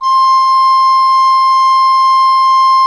Index of /90_sSampleCDs/Propeller Island - Cathedral Organ/Partition L/HOLZGEDKT MR